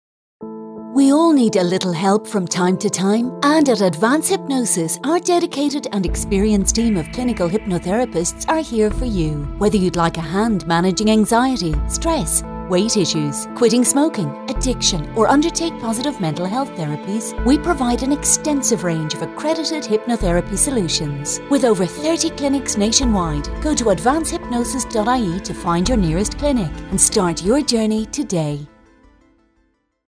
Our Latest Radio Advert
advance-hypnosis-ad-lmfm-dec-21.mp3